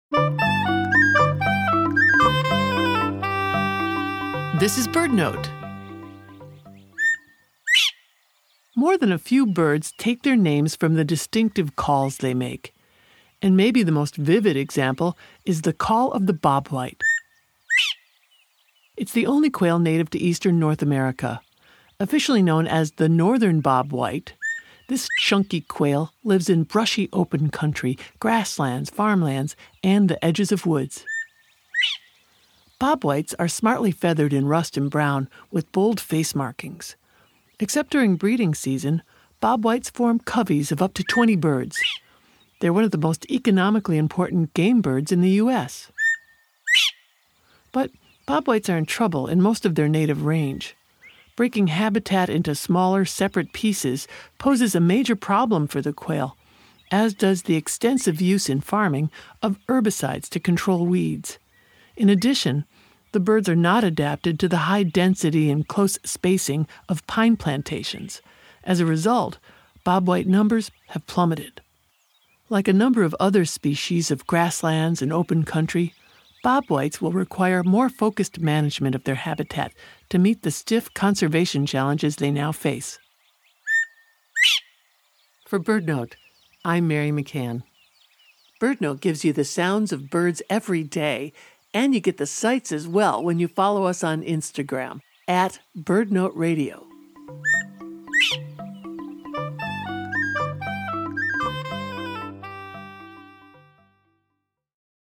The Northern Bobwhite — many call it just the Bobwhite — has an unmistakable call, which is also the source of its name.